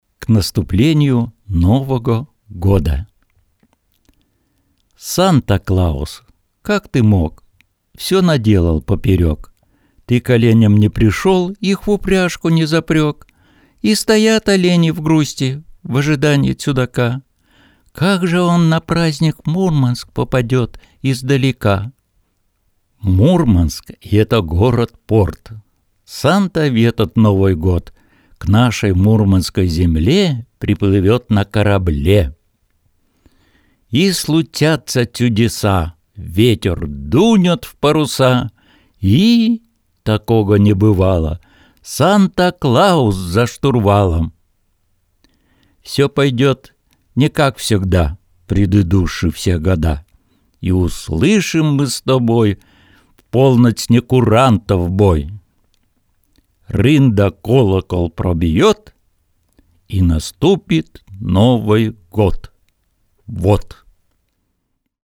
Сурядов В. А. Сказки Беломорья: на родном говоре